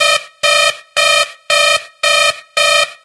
ta4_jetpack_alarm.ogg